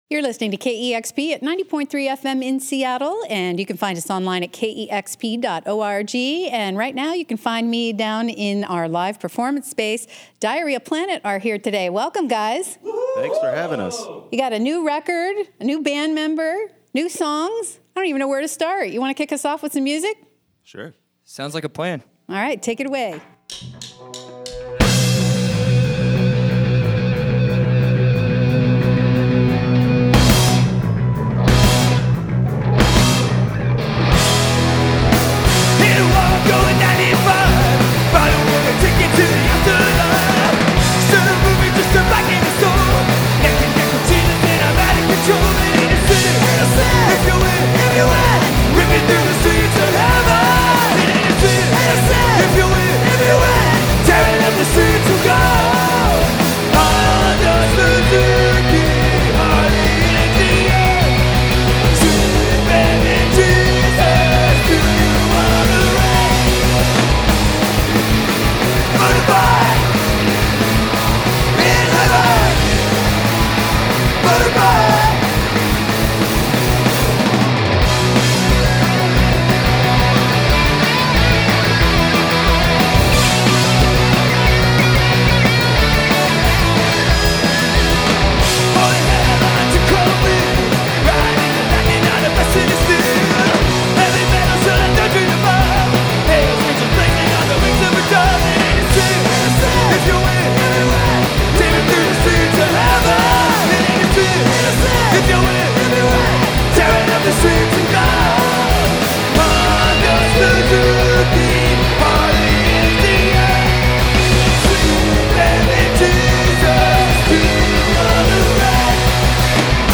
guitar solos
studios
air guitar-worthy anthems